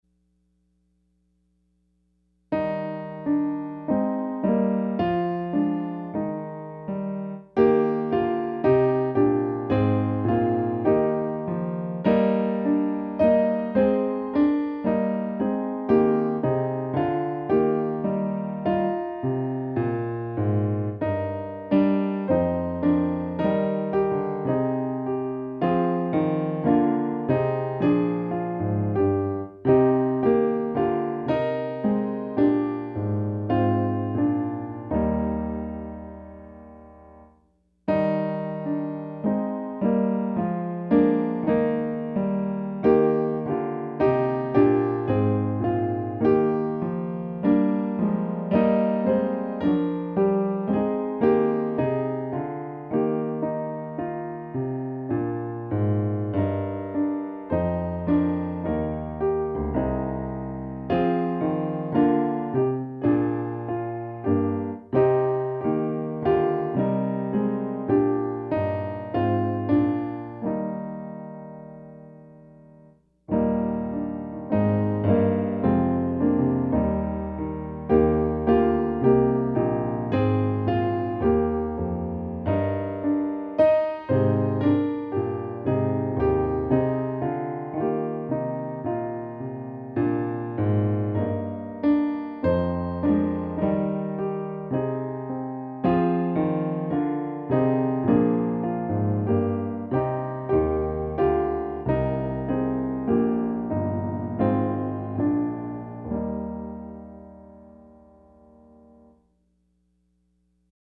Offering Hymn: Fairest Lord Jesus